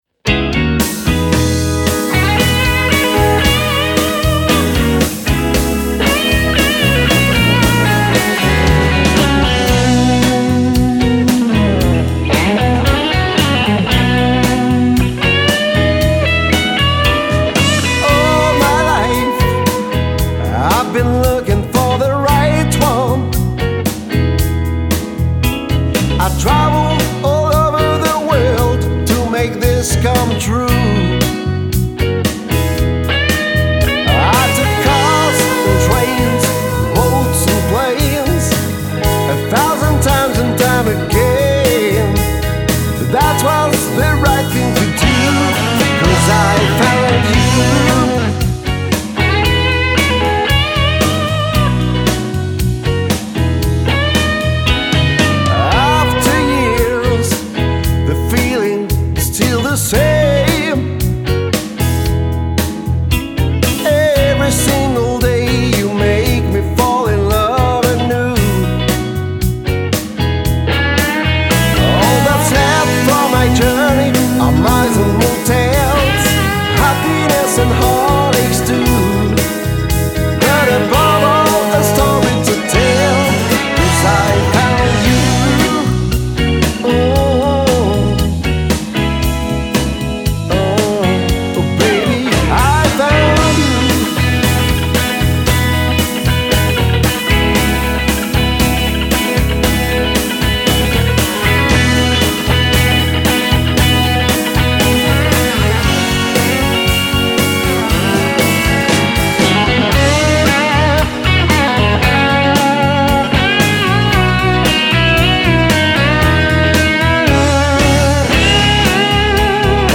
Lead Vocals, Backing Vocals, Lead Guitar, Rhythm Guitar
Organ [Hammond B3]